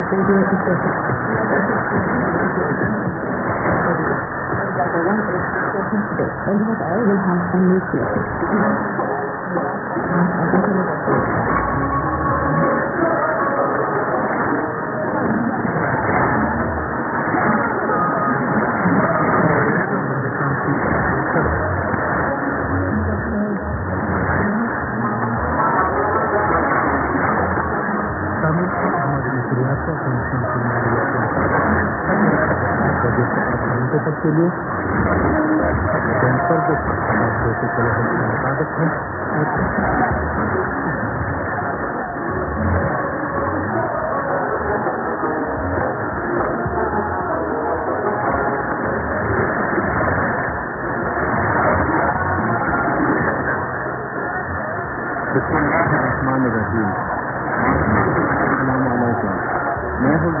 ->ST+ID(man)->